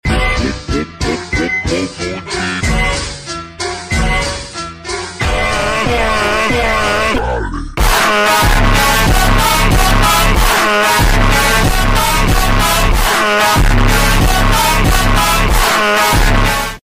Car Crash Simulador FlexicX . sound effects free download